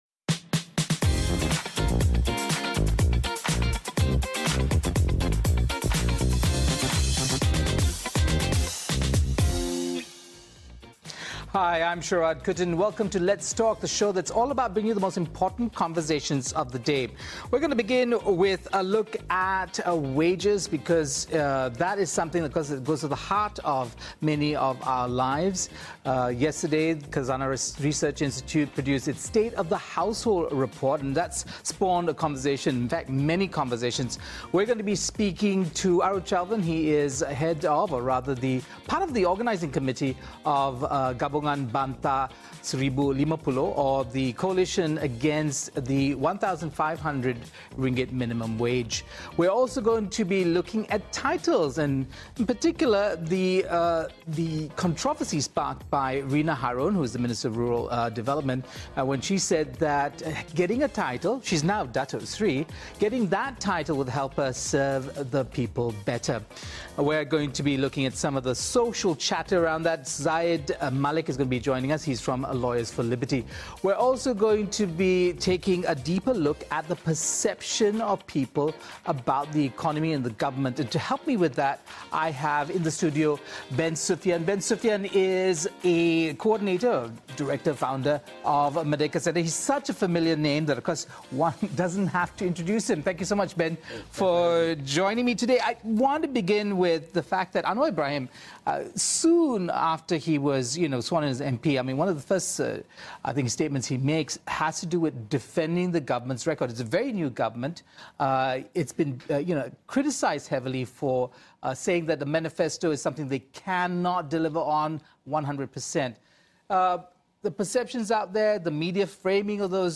have in the studio